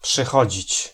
Ääntäminen
IPA: [koː.mǝⁿ]